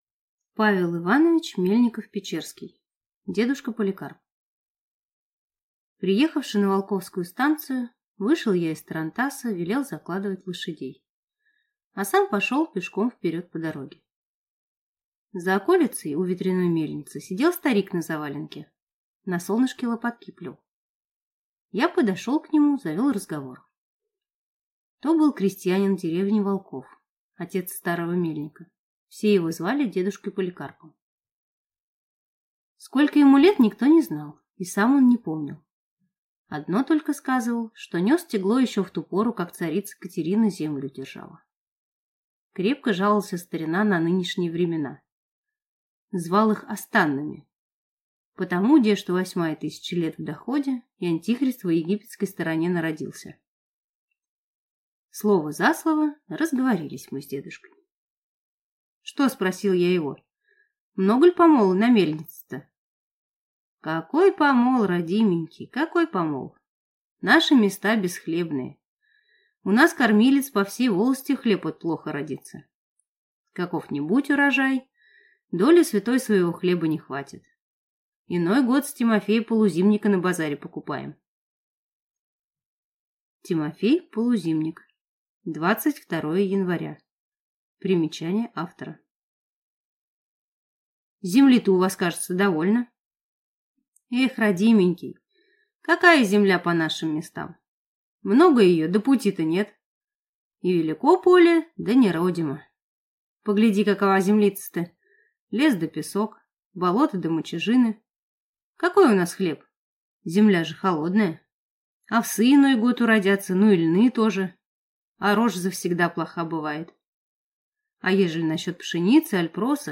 Аудиокнига Дедушка Поликарп | Библиотека аудиокниг